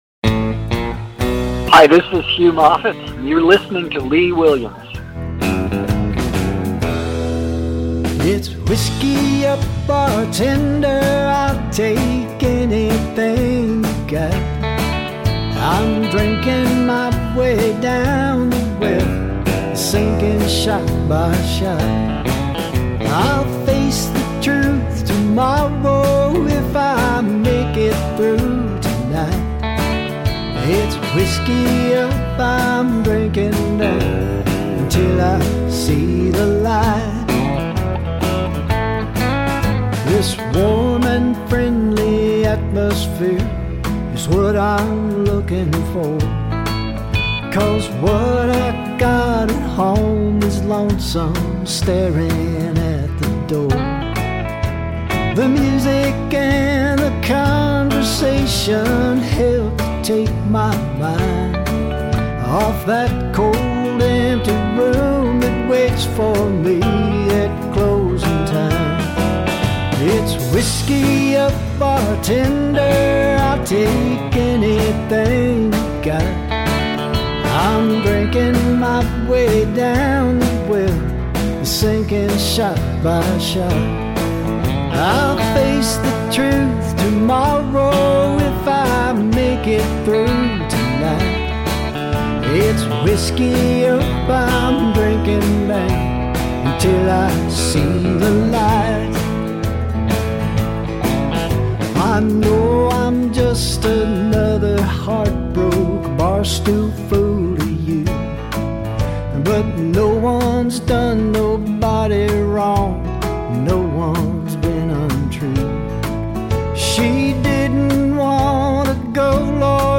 Sound quality not 100%